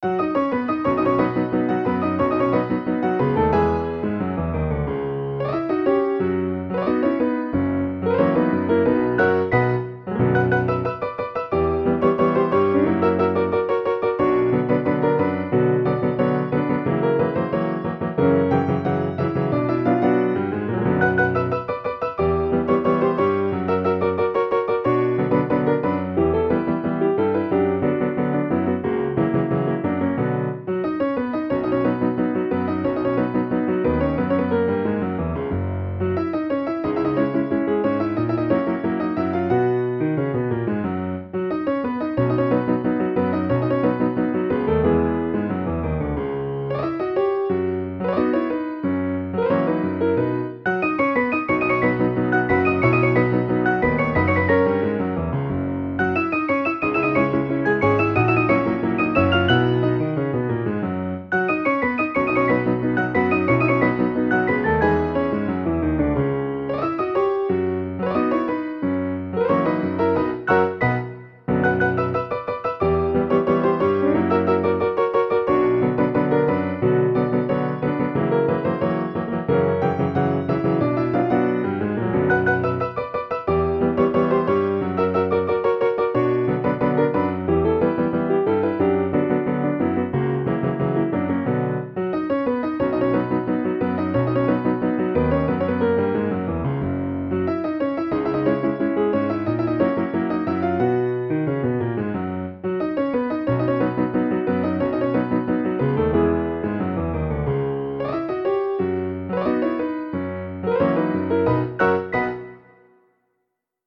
Tango
Rollo de pianola [Madrid, Diana, hacia 1925]